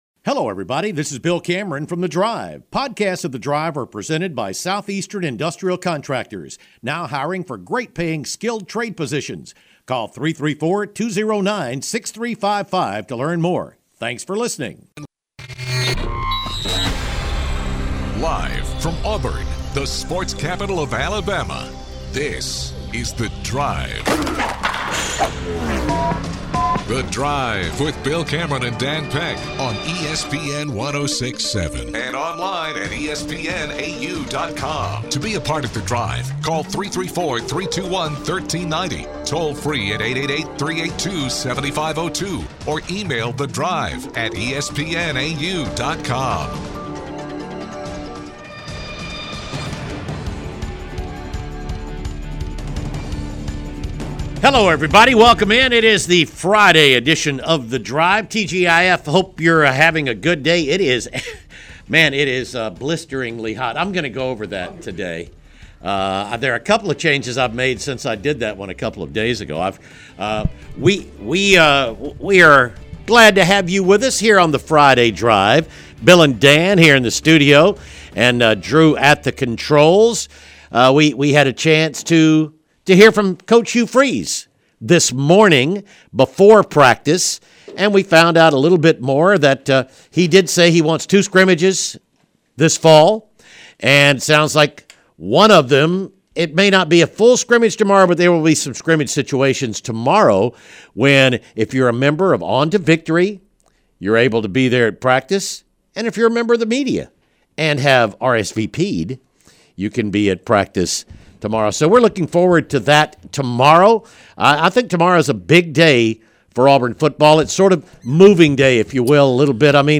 Audio from Fall Camp